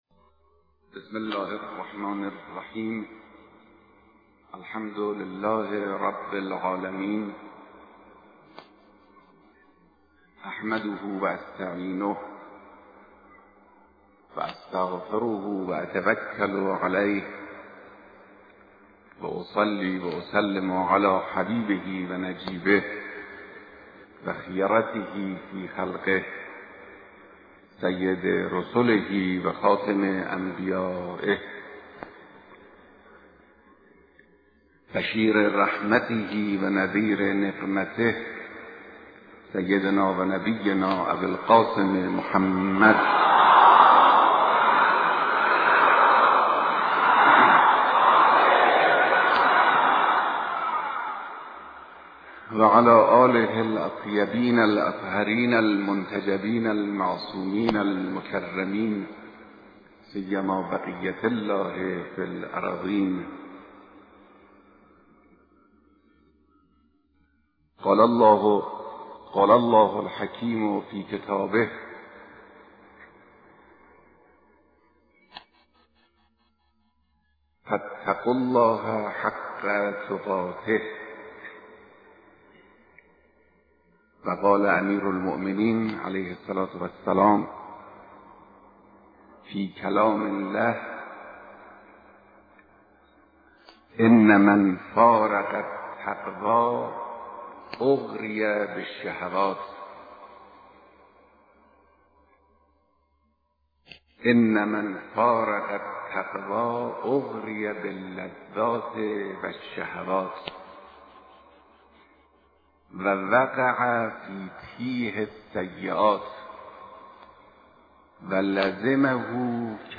خطبه‌هاى نماز جمعه